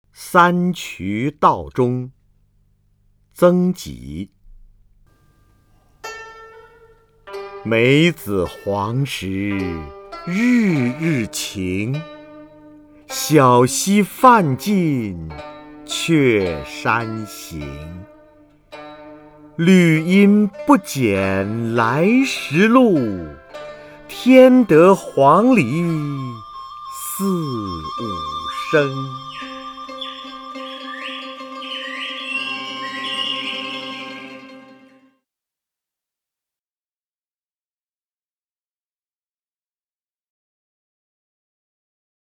瞿弦和朗诵：《三衢道中》(（南宋）曾几) （南宋）曾几 名家朗诵欣赏瞿弦和 语文PLUS
（南宋）曾几 文选 （南宋）曾几： 瞿弦和朗诵：《三衢道中》(（南宋）曾几) / 名家朗诵欣赏 瞿弦和